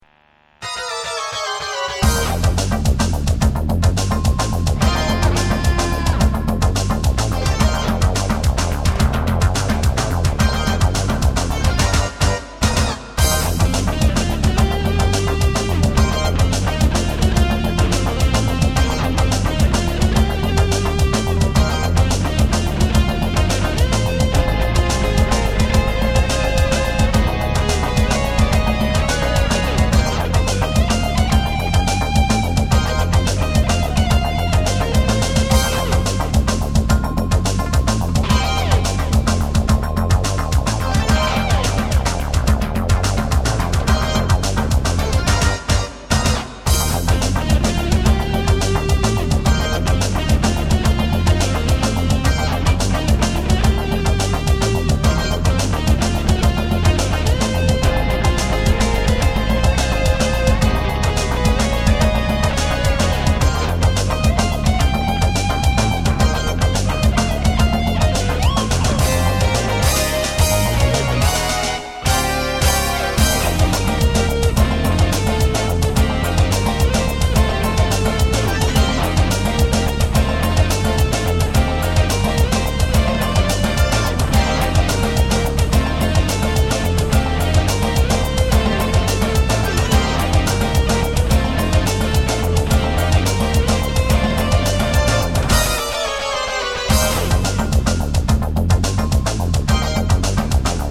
ピックアップはスピネックスでメローなサウンドが最高です。
確実に他のＳＧとは違います。もっと甘く伸びやかで、しかも荒くないこの音はＳＧ−Ｉ独特のものです。
特にセンターのシングルコイルの威力は絶大で、ストラトのハーフトーンのような音まで作ることができます。